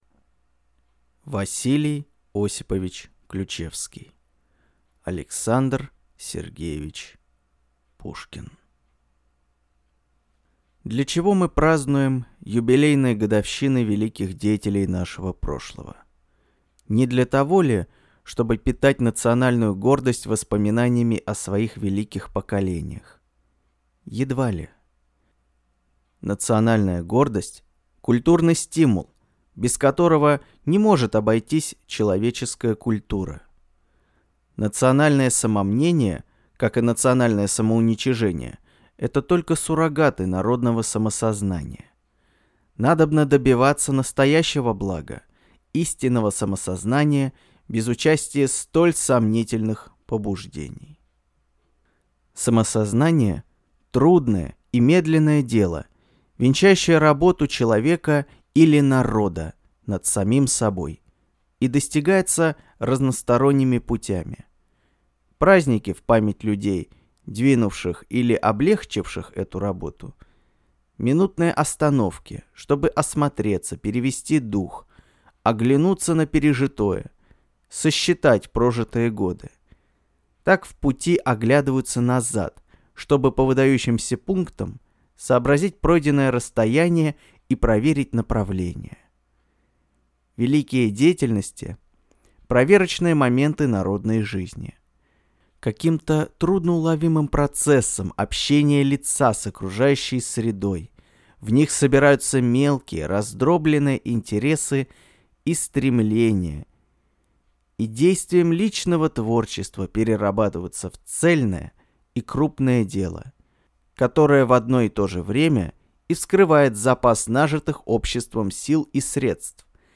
Аудиокнига А.С. Пушкин | Библиотека аудиокниг